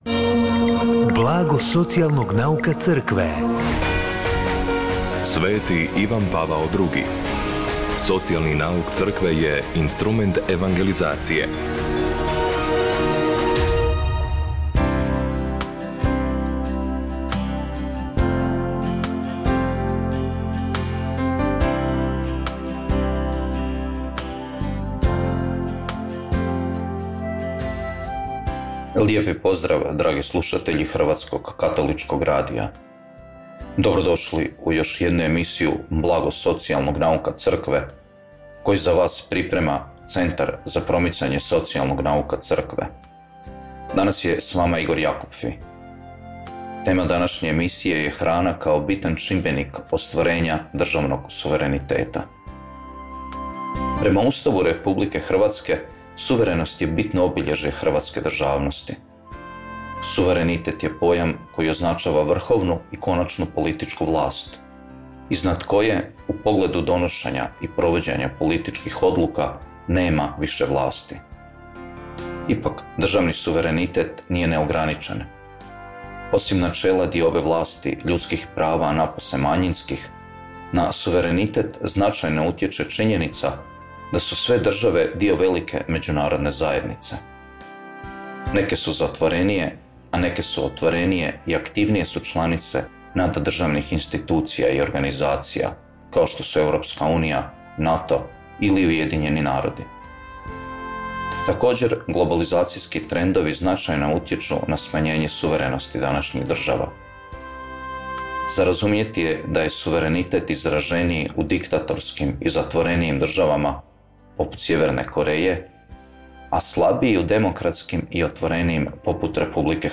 Emisiju na valovima HKR-a „Blago socijalnog nauka Crkve“ subotom u 16:30 emitiramo u suradnji s Centrom za promicanje socijalnog nauka Crkve Hrvatske biskupske konferencije.